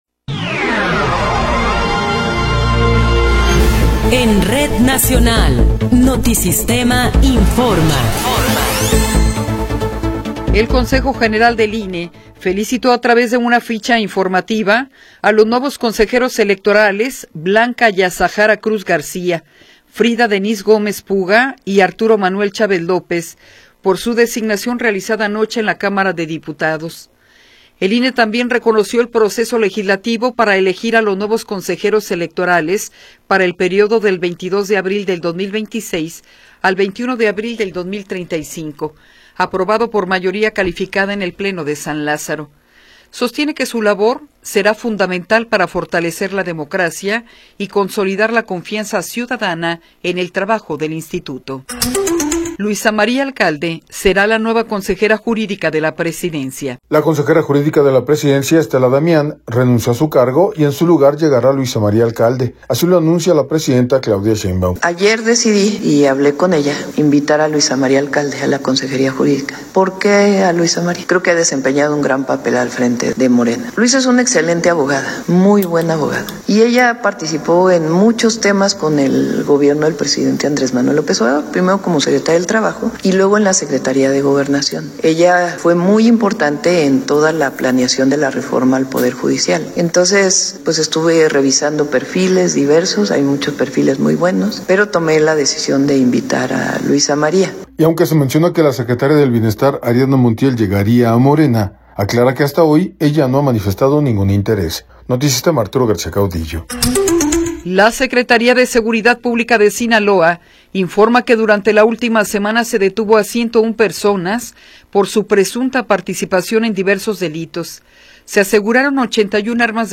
Noticiero 10 hrs. – 22 de Abril de 2026